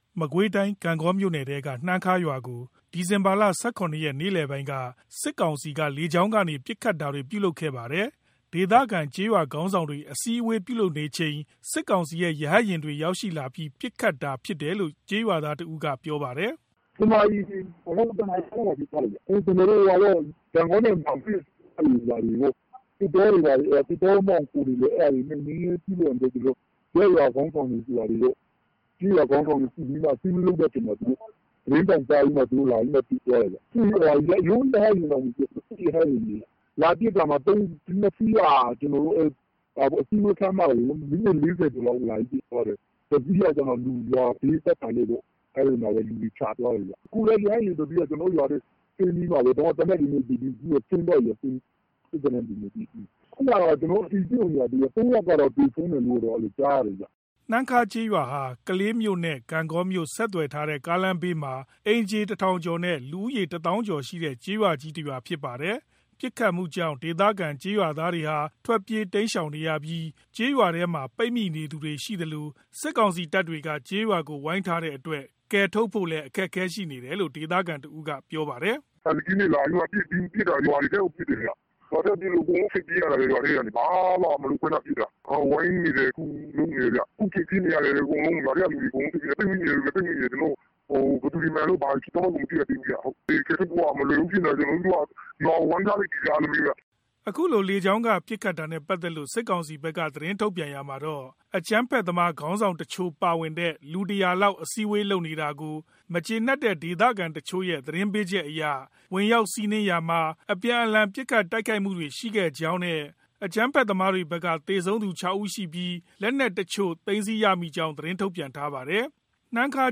မကွေးတိုင်း ဂန့်ဂေါမြို့နယ် နှမ်းခါးရွာကို စစ်ကောင်စီက လေကြောင်းကနေ ပစ်ခတ်တာတွေပြုလုပ်ပြီး ကျေးရွာကို ပိတ်ဆို့ထားတယ်လို့ ဒေသခံတွေက ပြောပါတယ်။ ဒေသခံကျေးရွာသား အများအပြား ထွက်ပြေး တိမ်းရှောင်နေရပြီး ရွာထဲမှာ ပိတ်မိနေသူတွေလည်းရှိပါတယ်။ ရန်ကုန်ကလာတဲ့သတင်းကို